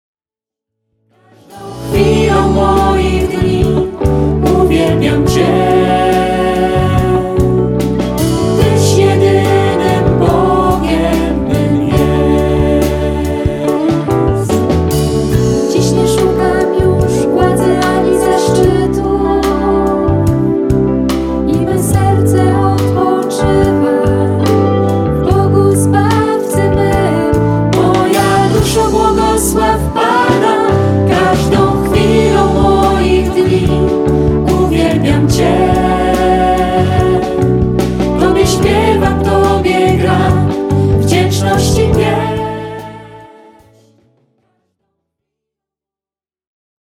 De très beaux chants d'assemblée